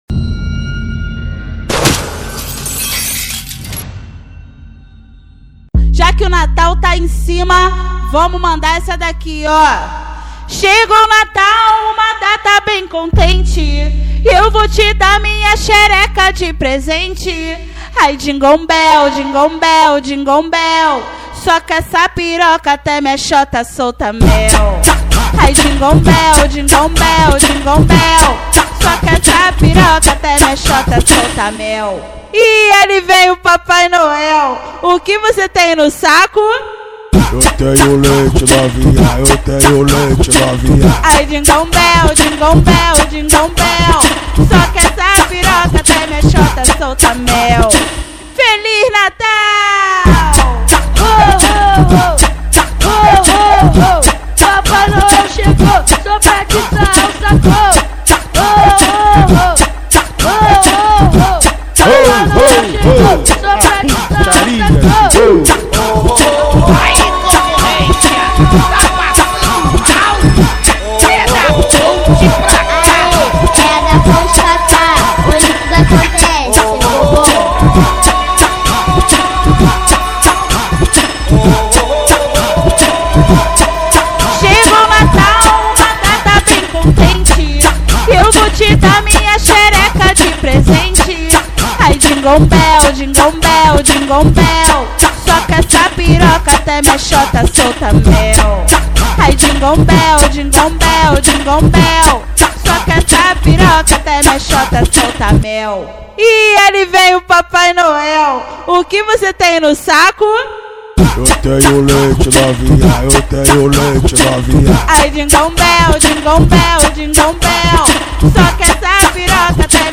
2024-12-31 19:20:33 Gênero: MPB Views